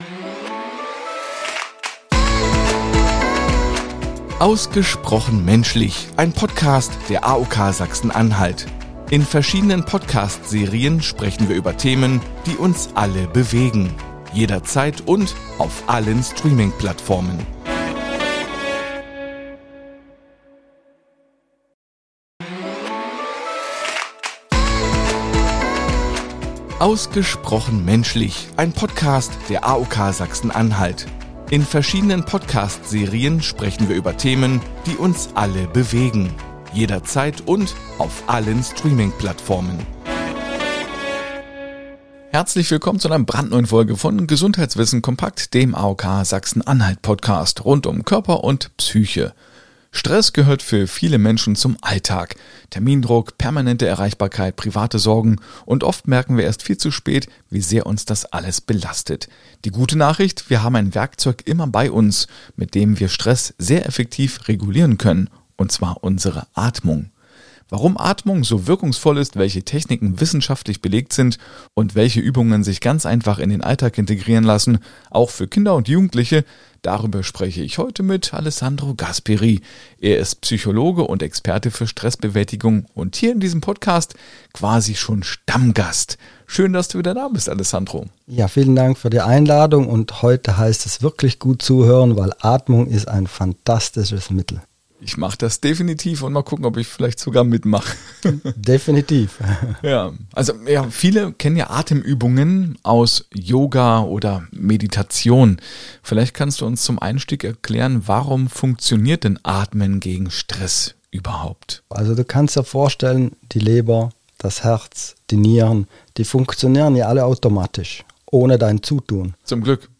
Er ist Psychologe und Experte für Stressbewältigung.